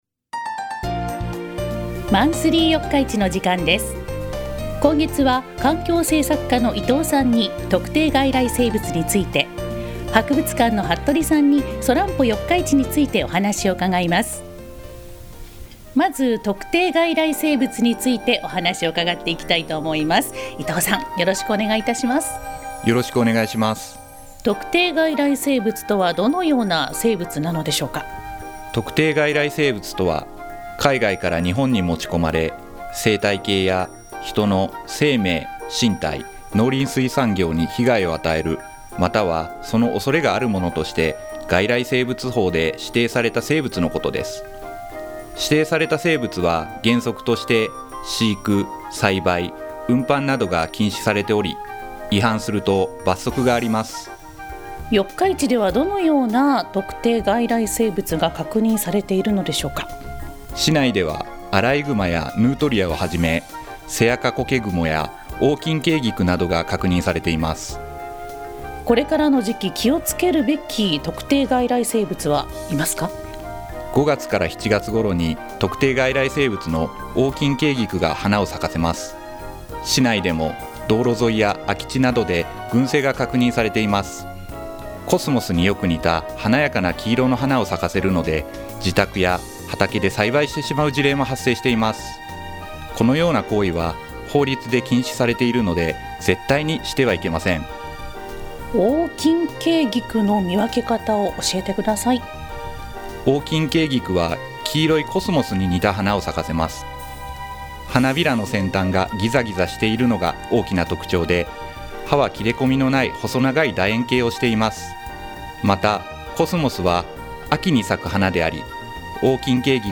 シー・ティー・ワイ エフエム（76.8MHz）で放送しています！